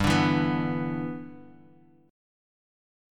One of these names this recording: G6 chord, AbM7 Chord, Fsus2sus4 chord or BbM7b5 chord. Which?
G6 chord